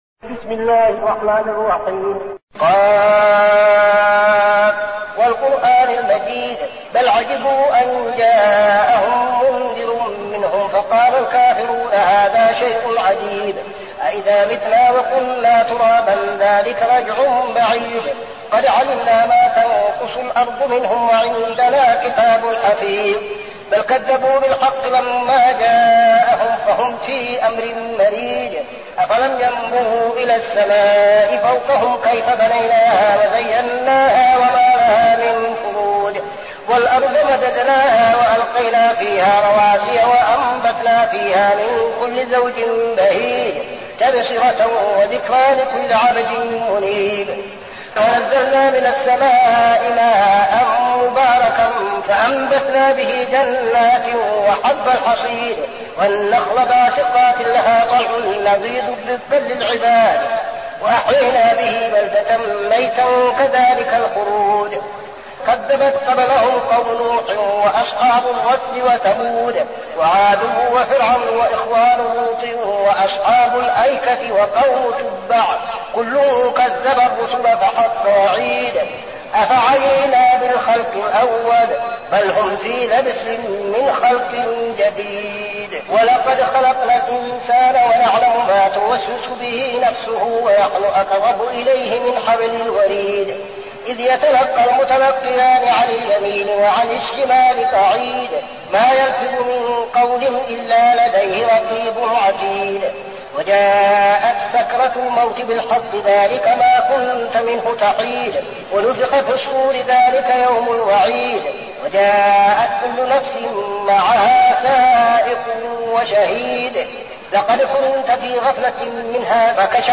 المكان: المسجد النبوي الشيخ: فضيلة الشيخ عبدالعزيز بن صالح فضيلة الشيخ عبدالعزيز بن صالح ق